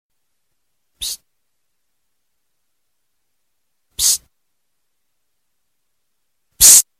Pst pst pst mp3 sound ringtone free download
Animals sounds